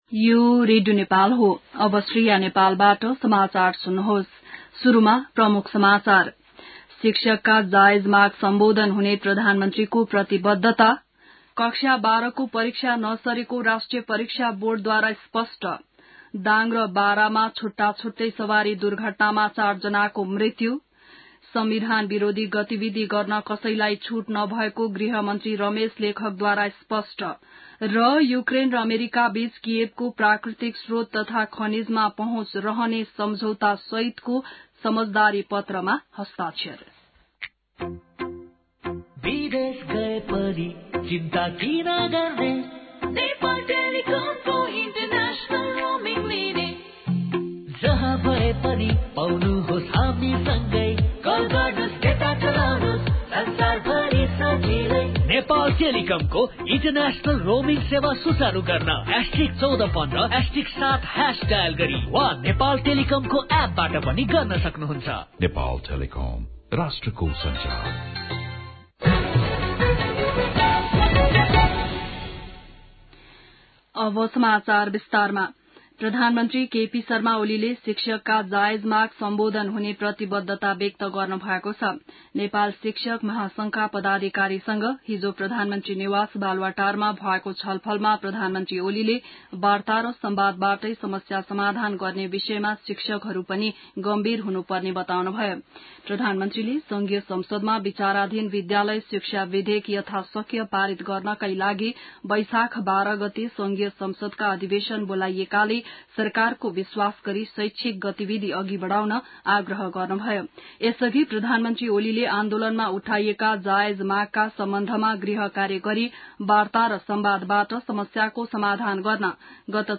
An online outlet of Nepal's national radio broadcaster
बिहान ७ बजेको नेपाली समाचार : ६ वैशाख , २०८२